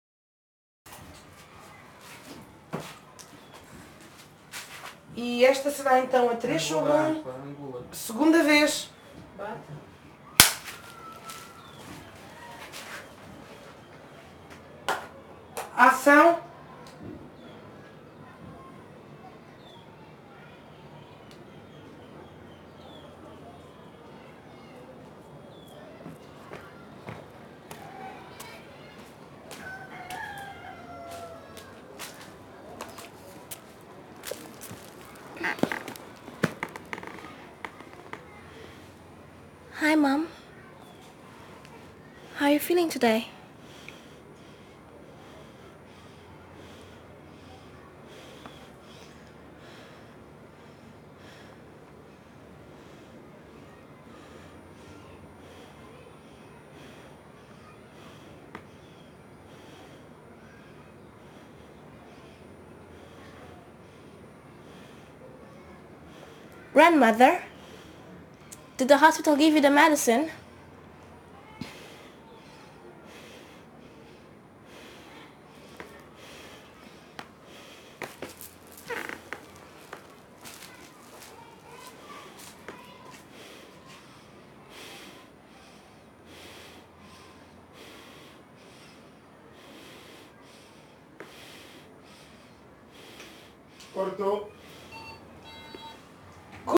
La vieille femme qui interprète la grand-mère n’est pas du tout actrice et c’est assez laborieux quant au texte et aux manipulations de la machine.
Dans ce champ comme dans le contrechamp, la perche se place sur le in, un micro sur pied s’occupe du off ou de l’amorce.
La République des enfants – 3 / 1 t2 – perche et micro off au centre